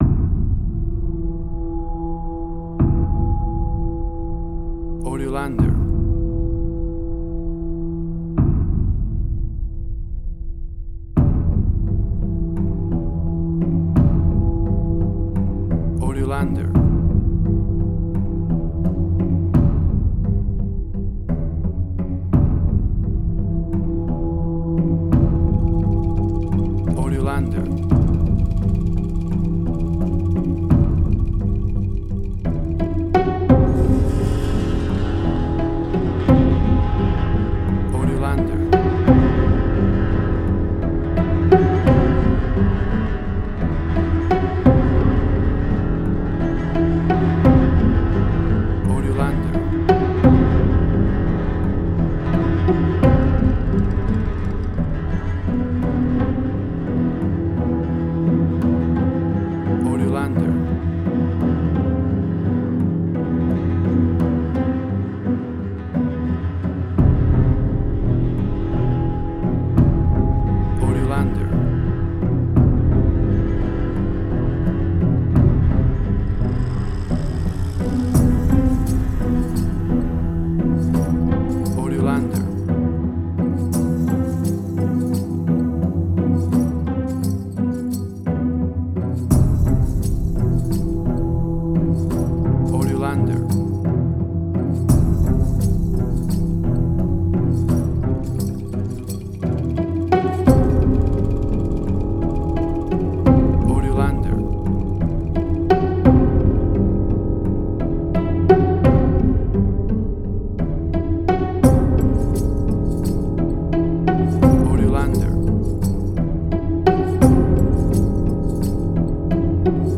Suspense, Drama, Quirky, Emotional.
WAV Sample Rate: 16-Bit stereo, 44.1 kHz
Tempo (BPM): 86